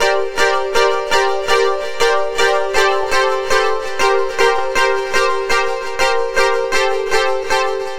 Session 08 - Electronic Piano.wav